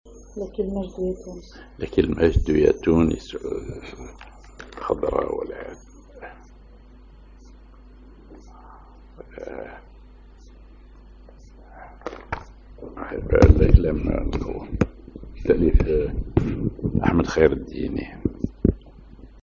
Maqam ar ماجور على النوا
genre نشيد